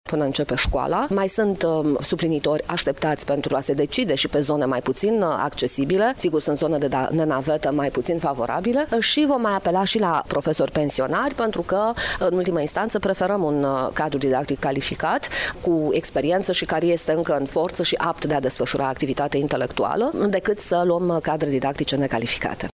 Inspectorul general al ISJ Iași, Camelia Gavrilă a declarat că se va apela la profesorii pensionari care constituie o alternativă pentru catedrele vacante: